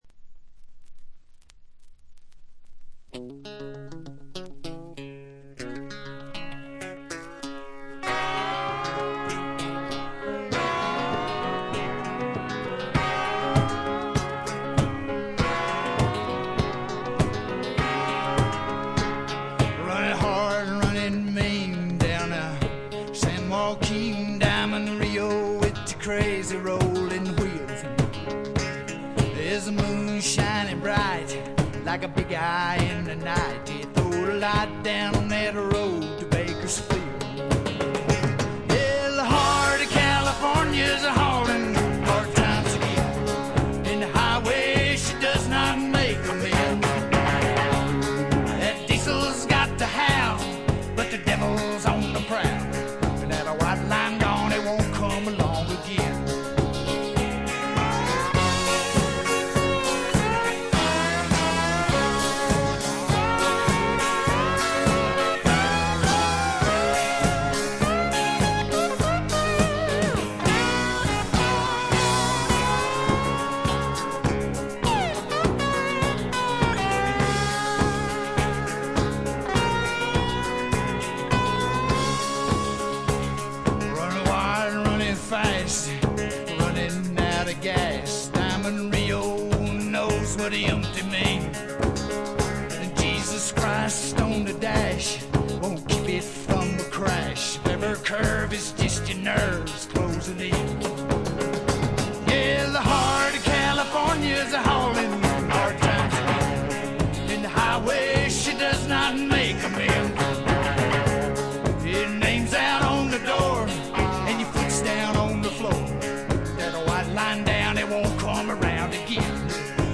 軽いノイズ感のみ。
試聴曲は現品からの取り込み音源です。